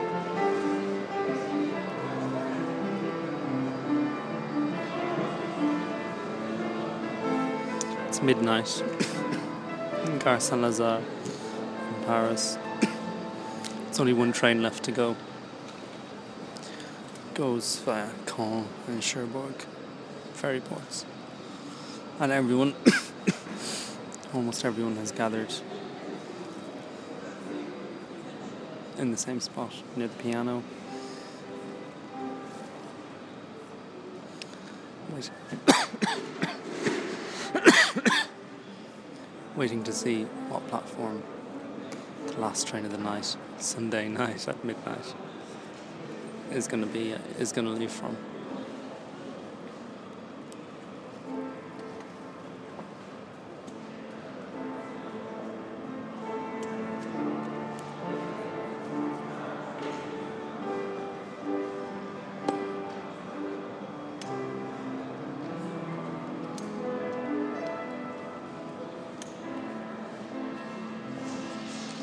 waiting for the last train from Gare St Lazare at midnight on a Sunday
Piano, train station